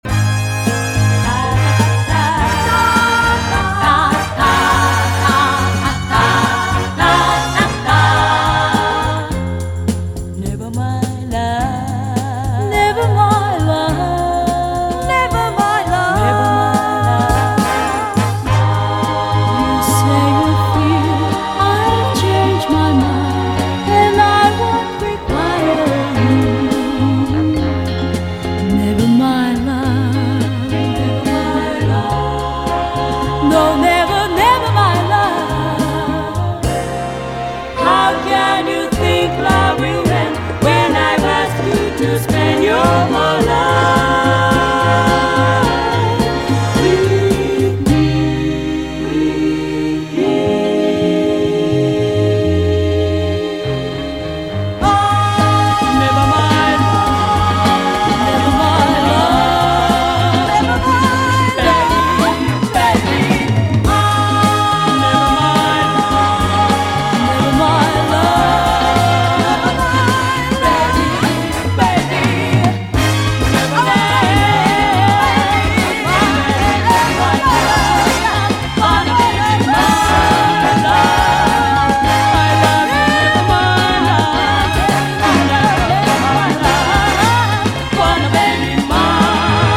ROCK / 60'S / SURF / SURF INST. / OLDIES / HOT ROD / INSTRO